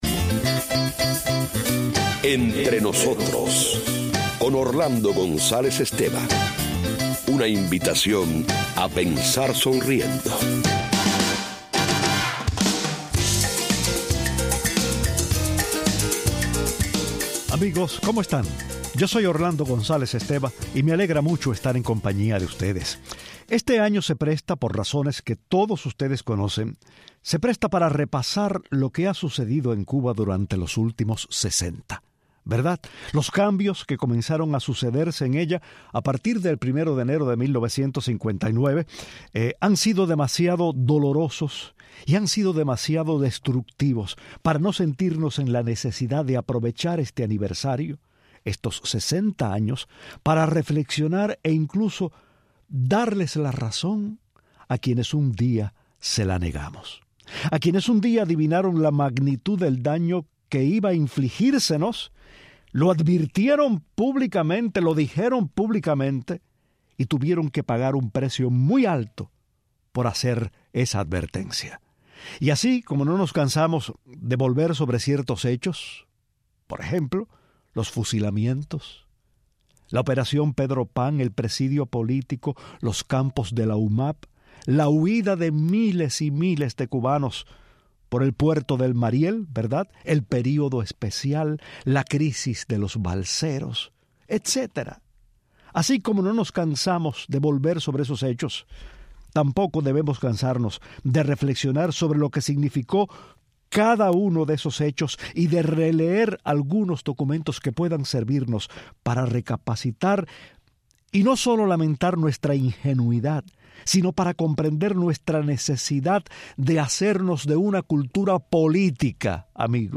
lee y comenta los párrafos finales de la carta escrita por el poeta cubano donde éste explica, antes de marcharse a España, por qué no puede unirse al "carro victorioso".